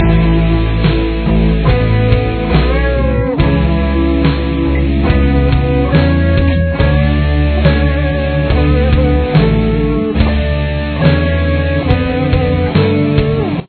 Below is the basic part for the main riff:
What you’re hearing is 3 guitar parts.